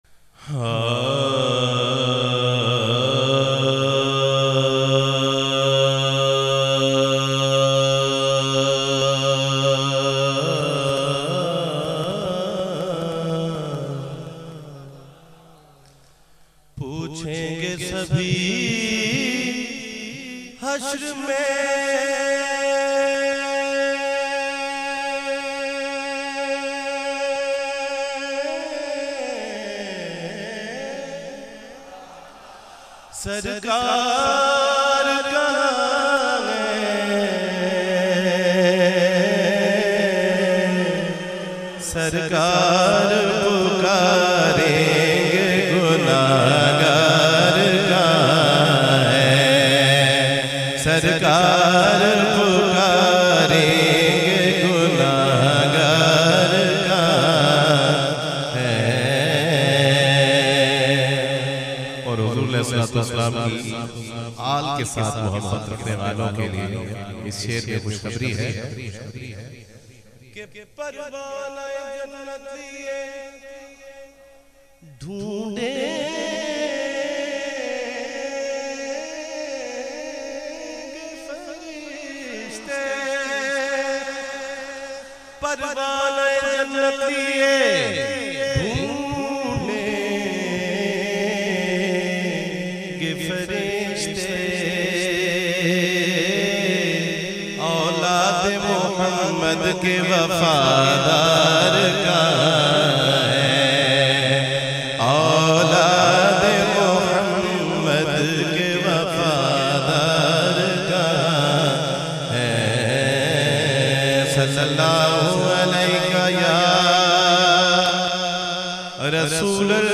Beautiful Naat